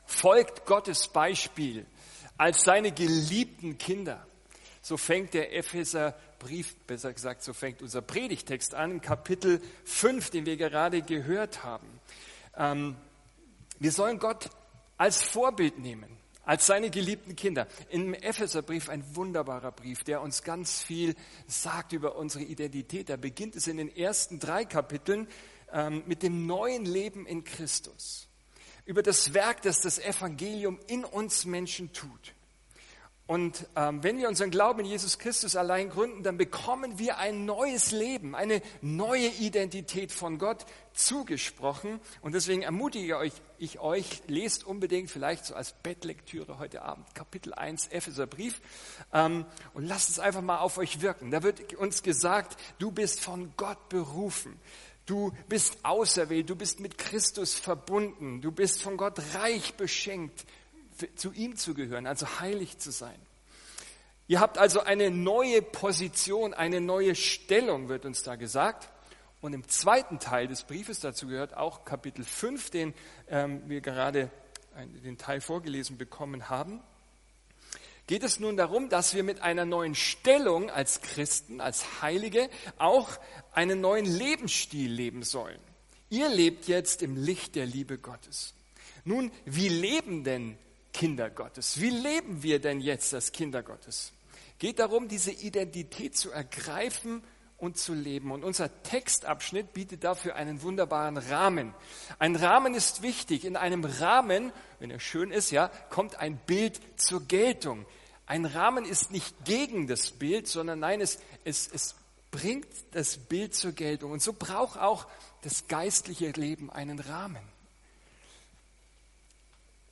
Ein Studienblatt zur Predigt ist im Ordner “Notes” verfügbar